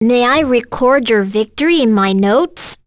voices / heroes / en
Leo-Vox_Skill3.wav